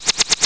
SFX飞镖飞刀箭矢连续发射命中目标游戏音效下载
SFX音效